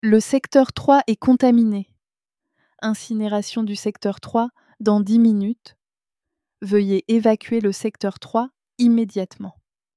Une voix féminine annonce alors froidement « Le secteur III est contaminé. Incinération du secteur III dans 10 minutes. Veuillez évacuer le secteur III immédiatement. » Le même message sera répété dans tout le laboratoire toutes les minutes.